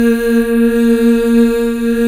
Index of /90_sSampleCDs/Club-50 - Foundations Roland/VOX_xFemale Ooz/VOX_xFm Ooz 2 S